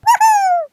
minion-yahoo.ogg